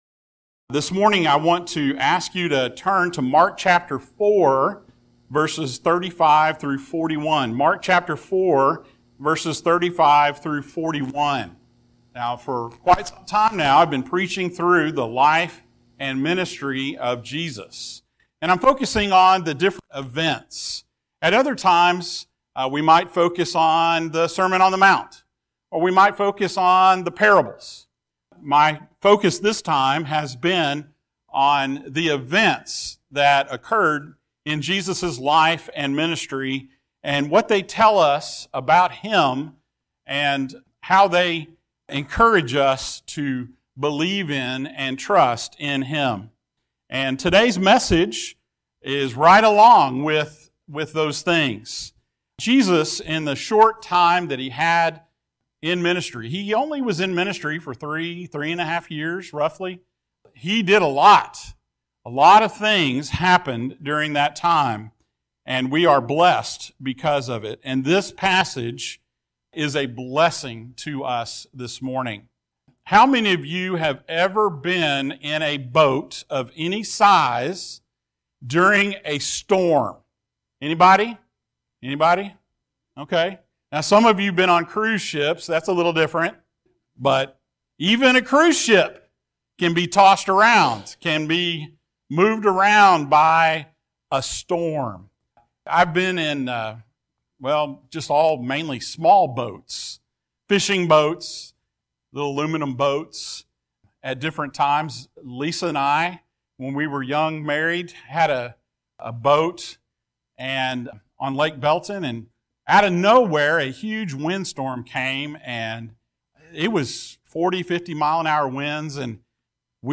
The Life and Ministry of Jesus Passage: Mark 4:35-41 Service Type: Sunday Morning Thank you for joining us.